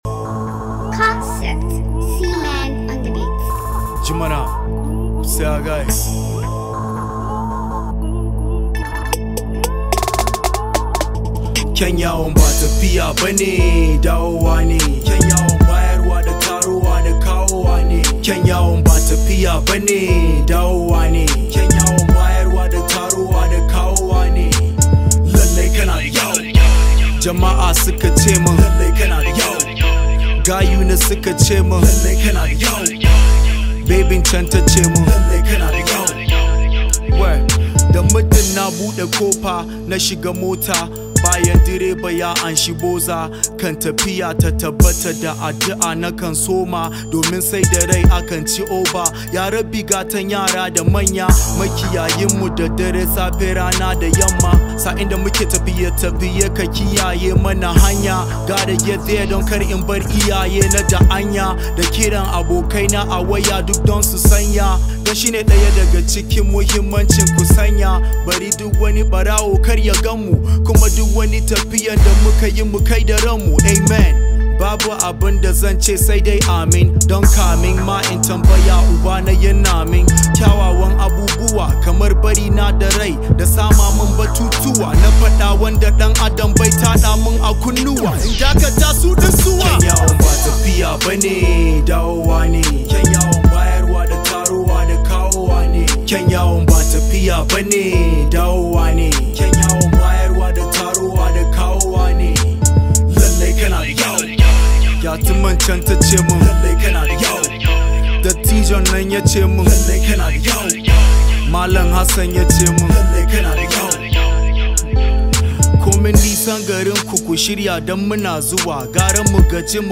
Northern Nigerian rave of the moment indigenous rapper